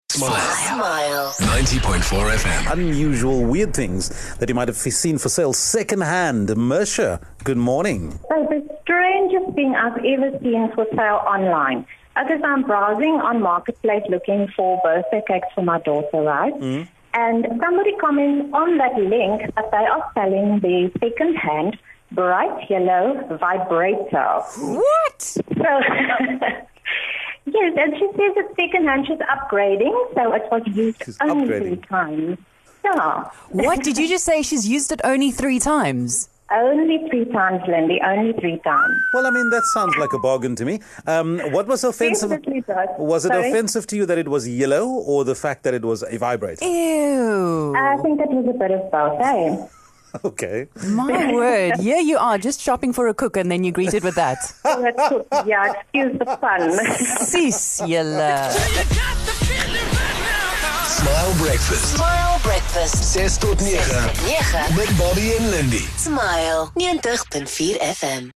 In a conversation about 2nd items for sale one caller told us about how an innocent search for a good birthday cake led to a discovery of something that has no business being sold on Facebook Marketplace.